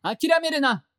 戦闘 バトル ボイス 声素材 – Battle Voice